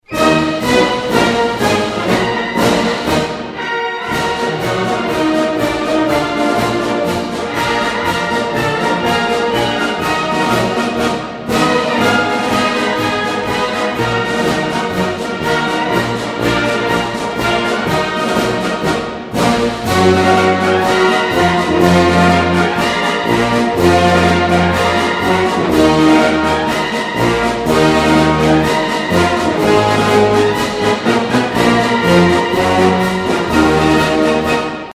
Категория: Классические | Дата: 09.12.2012|